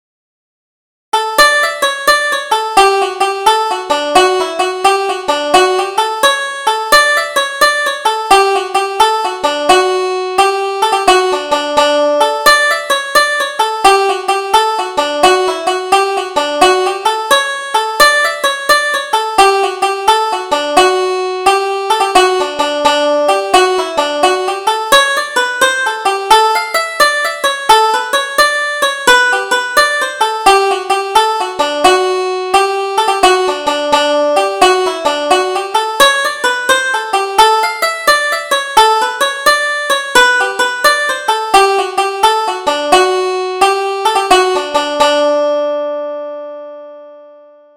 Double Jig: Farewell to My Troubles